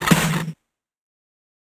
MB Hit (1).wav